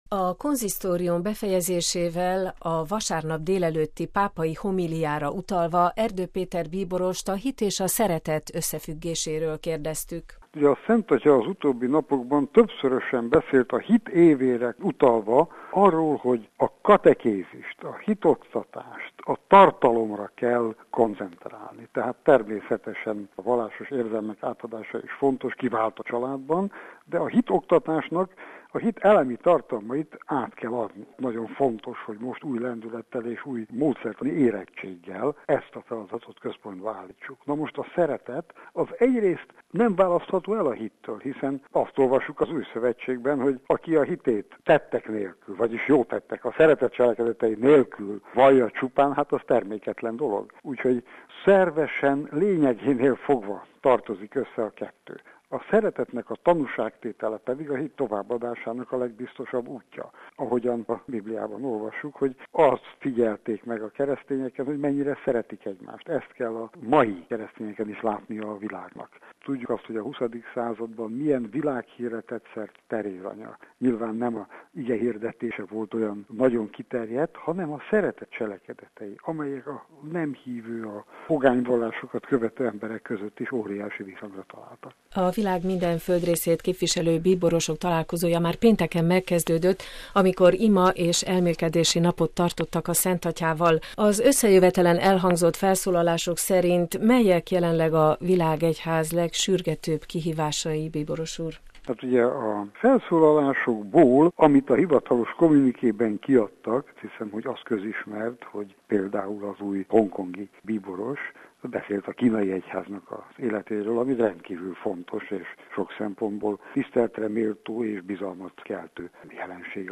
„A szeretet nem választható el a hittől” – interjú Erdő Péter bíborossal